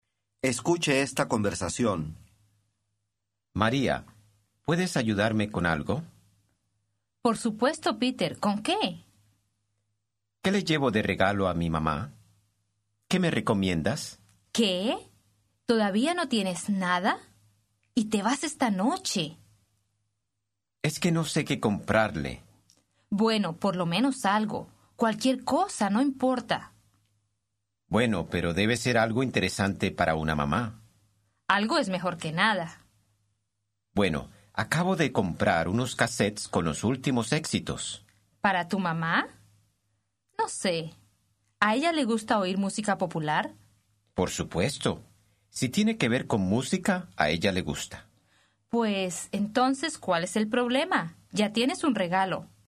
Аудио курс для самостоятельного изучения испанского языка.